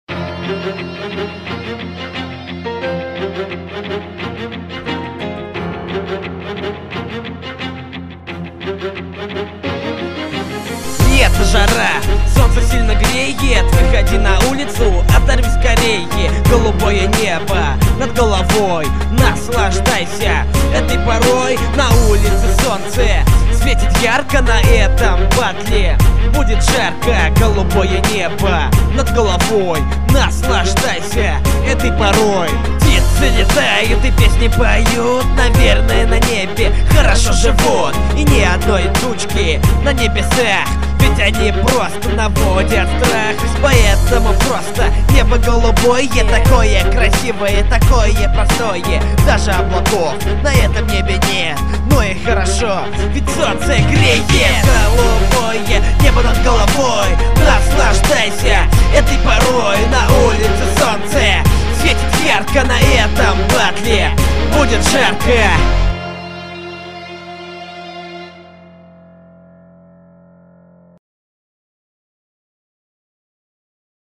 Салаватский рэпер
Жанр-рэп